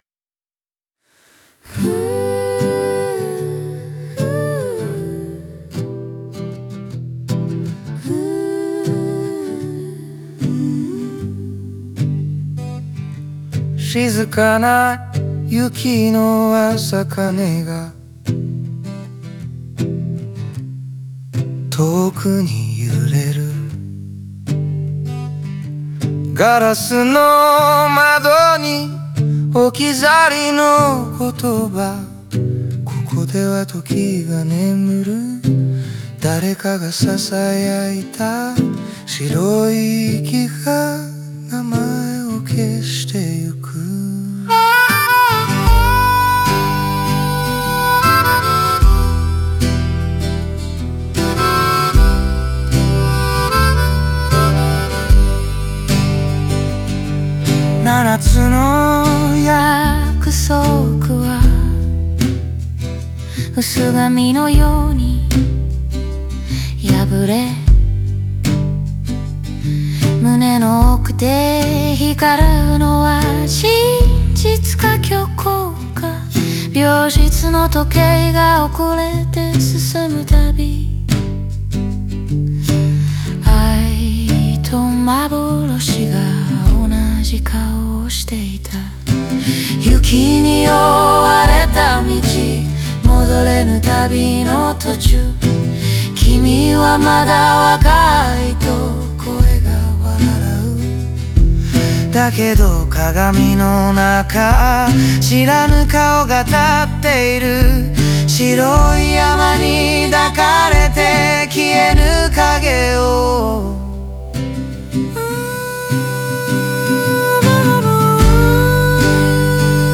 オリジナル曲♪
この歌詞は、雪に閉ざされた療養地を舞台に、人の愛や記憶、虚飾と真実の境界を探る物語をフォーク調で表現しています。